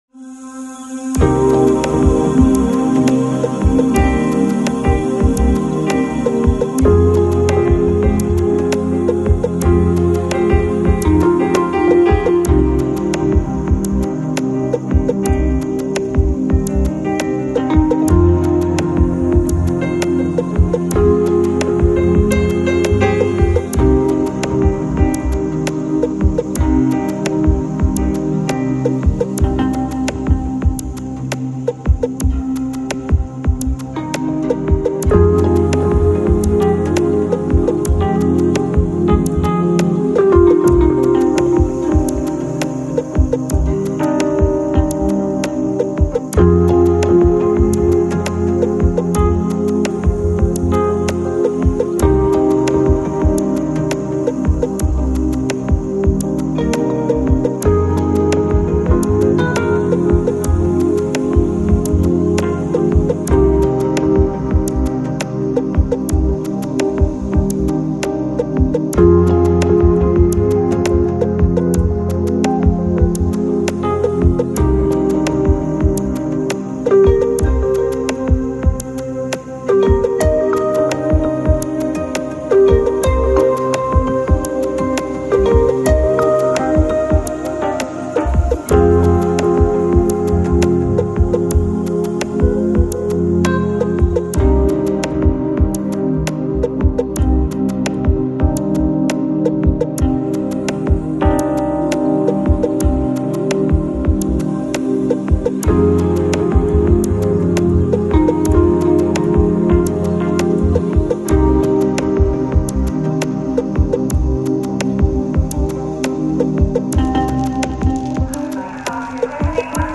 Жанр: Chillout, Chillhouse, Downbeat, Lounge